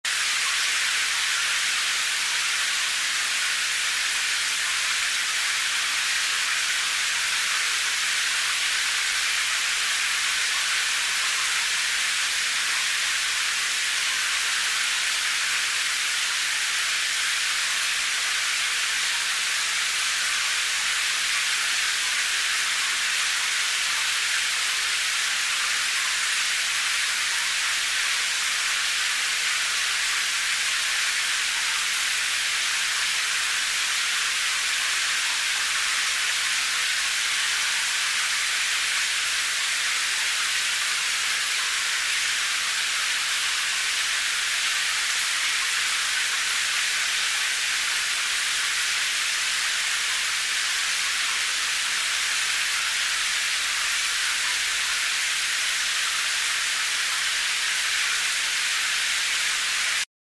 دانلود آهنگ باران 5 از افکت صوتی طبیعت و محیط
جلوه های صوتی
دانلود صدای باران 5 از ساعد نیوز با لینک مستقیم و کیفیت بالا
برچسب: دانلود آهنگ های افکت صوتی طبیعت و محیط دانلود آلبوم صدای نم نم باران از افکت صوتی طبیعت و محیط